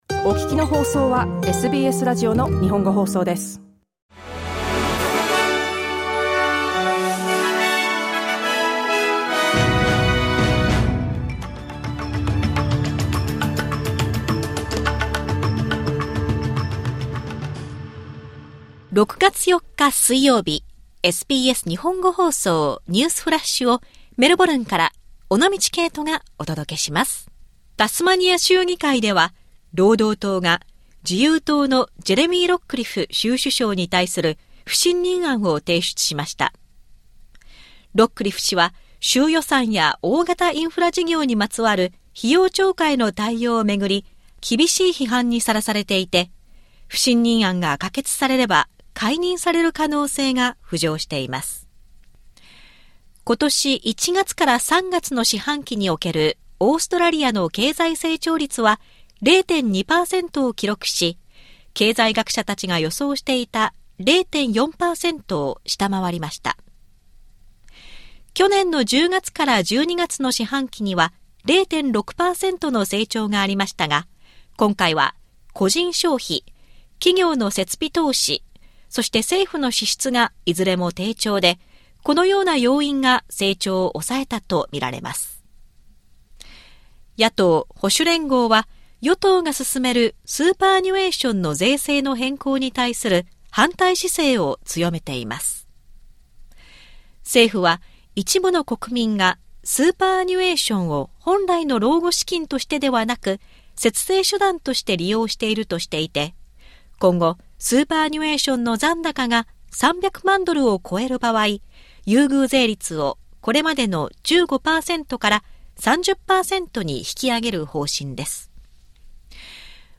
SBS日本語放送ニュースフラッシュ 6月4日 水曜日